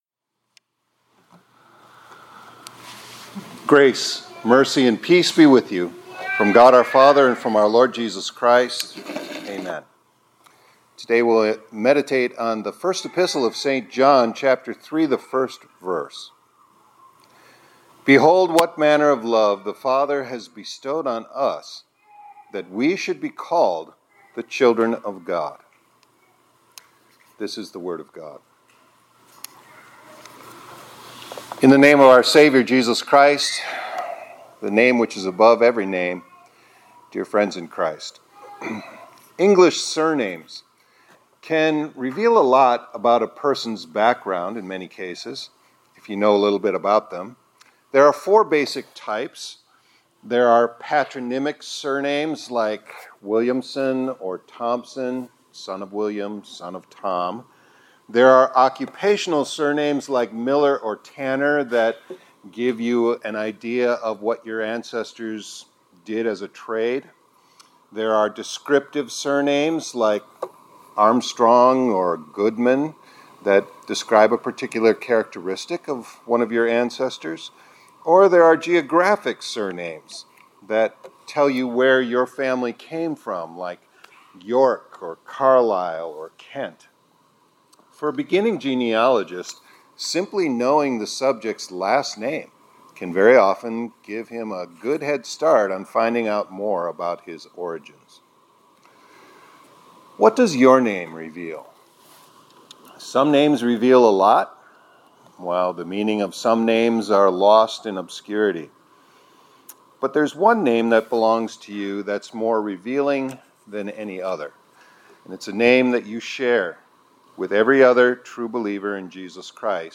2025-01-14 ILC Chapel — “CHILD OF GOD” — A Name That Reveals How Much God Loves Us